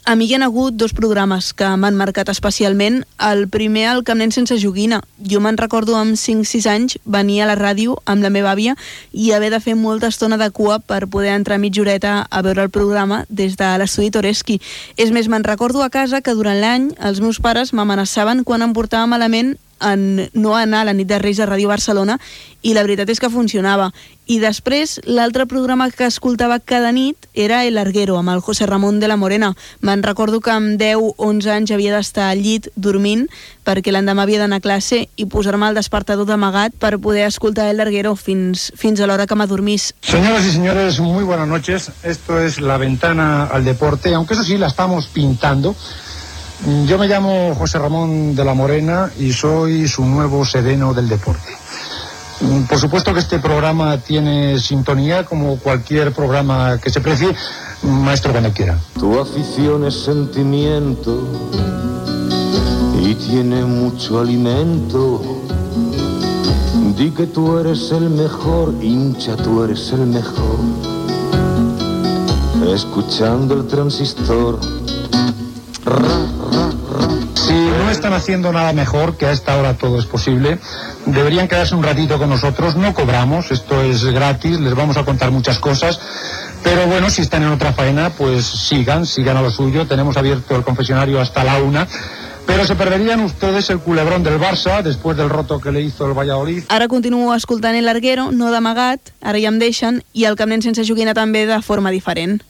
Divulgació